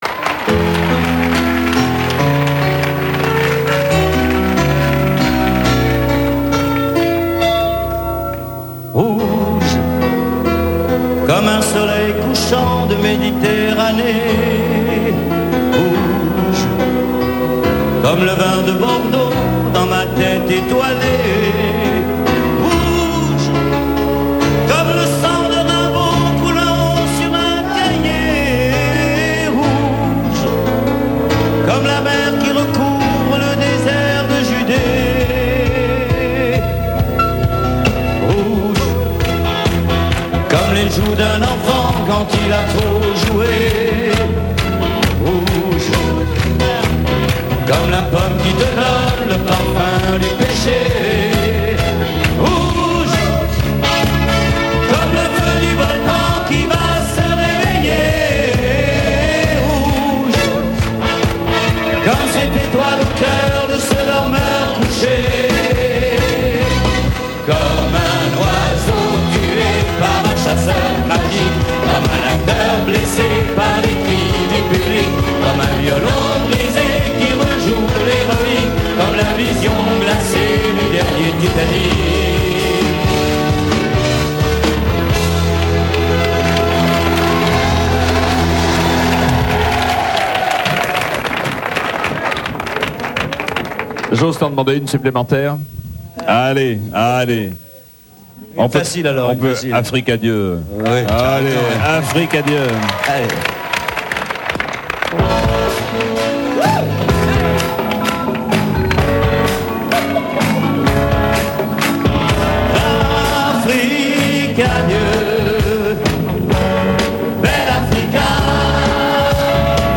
TV-Radio en direct (hors concerts)